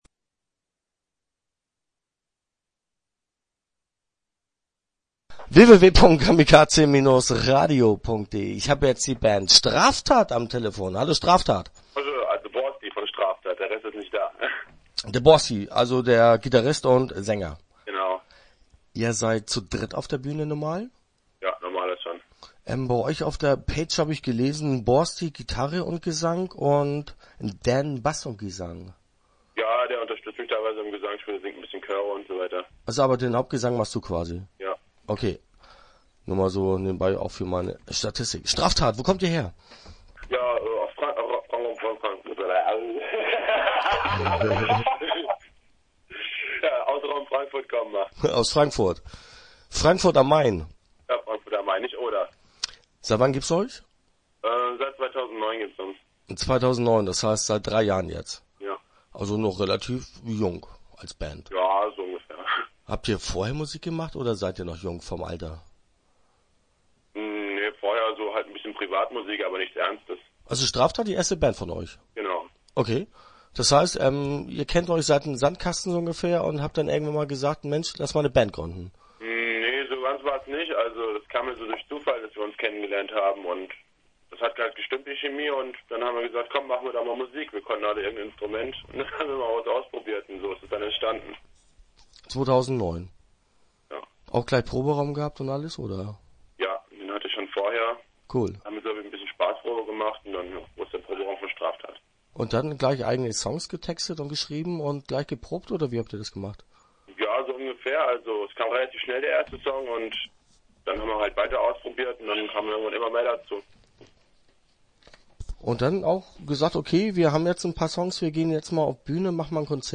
Start » Interviews » Straftat